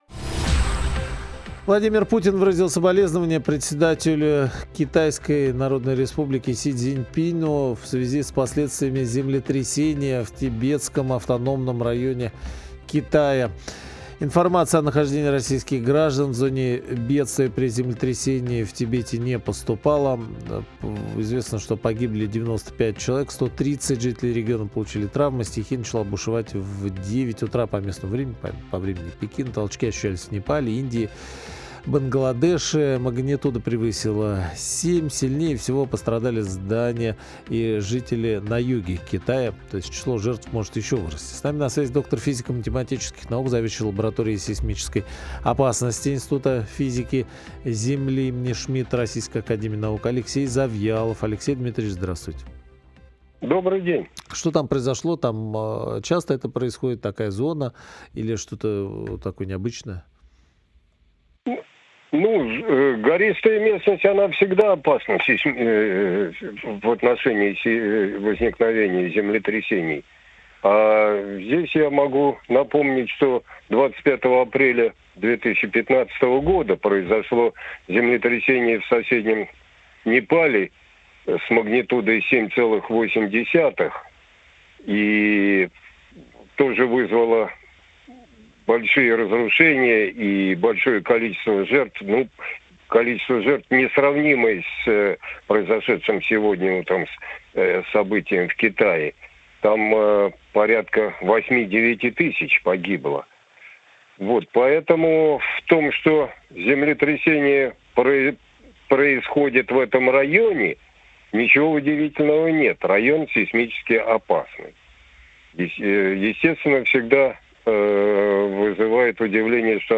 Запись эфира радио «